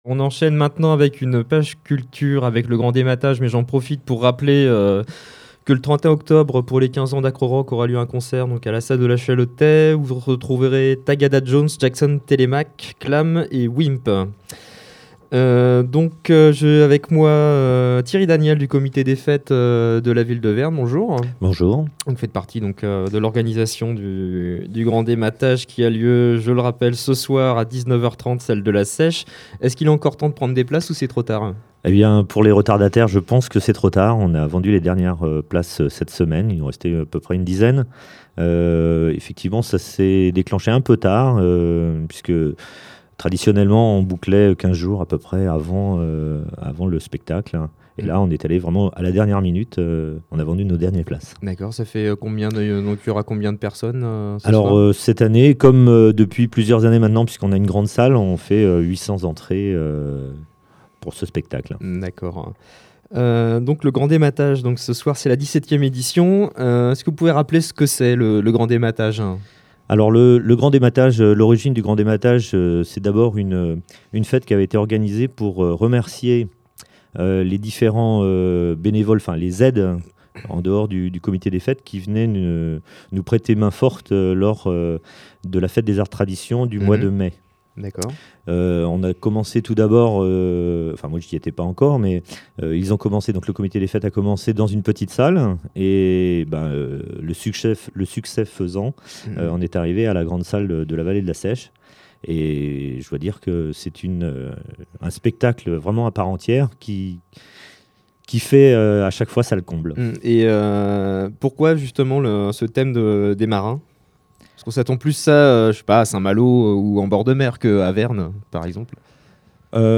Interview radio campus Rennes en direct (24/10/2009) :
interviewtotal-chants.mp3